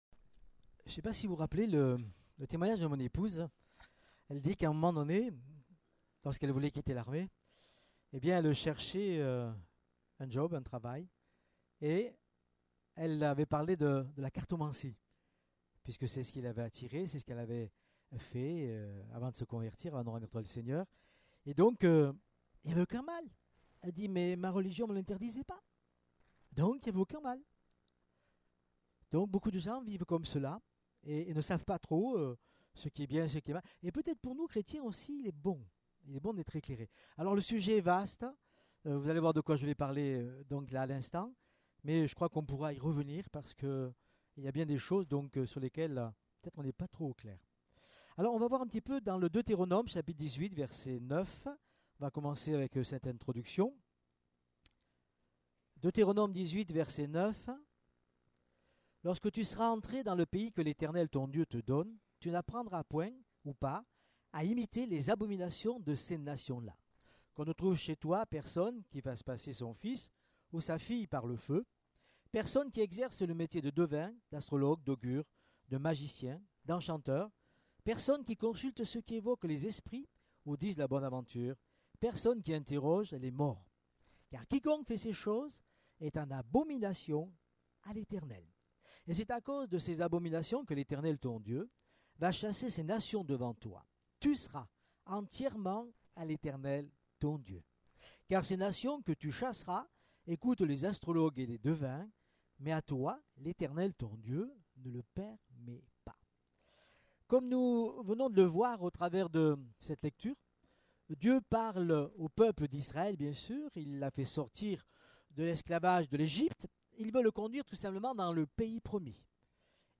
Type De Service: Evangélisation